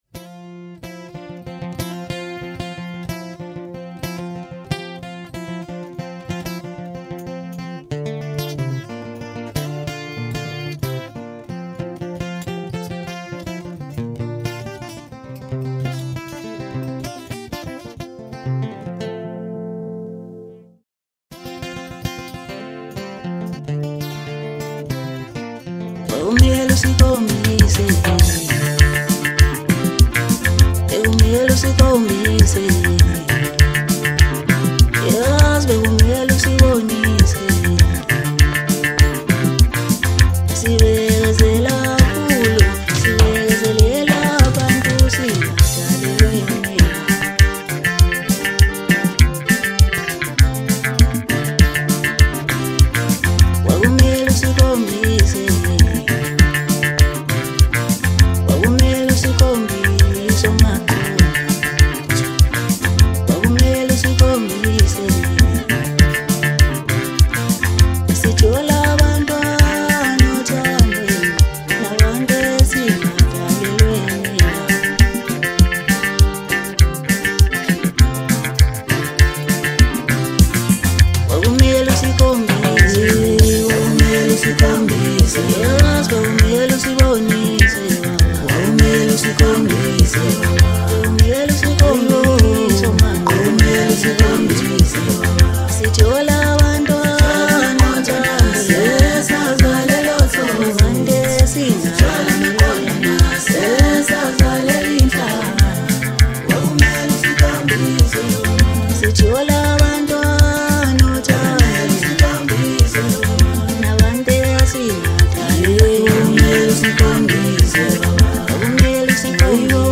is a groovy anthem